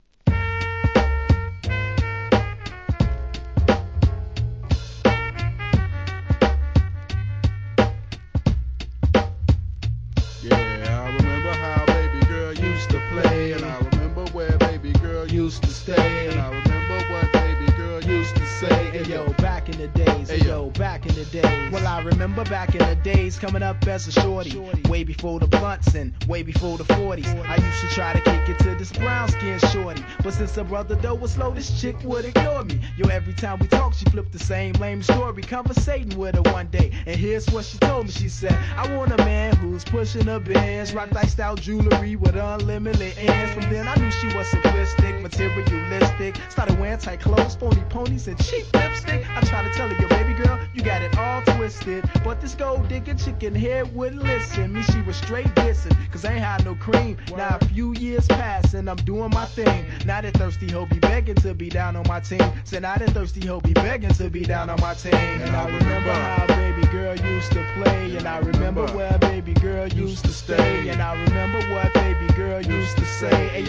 1. HIP HOP/R&B
好JAZZYアングラ!!